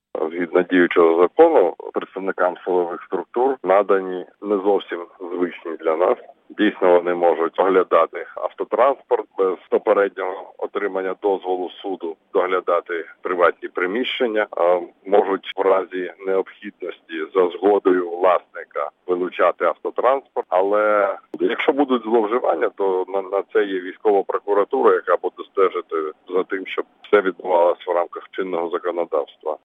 Прослухати репортаж Хочеш знати більше - слухай новини на Українському радіо!
Відсьогодні починає діяти Особливий порядок на Донбасі, у зв'язку зі зміною формату АТО на Операцію об’єднаних сил. Новий порядок надає органам сектору безпеки і оборони спеціальні повноваження, необхідні для впровадження заходів щодо гарантування національної безпеки у Донецькій і Луганській областях, сказав Українському радіо заступник міністра Міністерства з питань тимчасово окупованих територій Георгій Тука .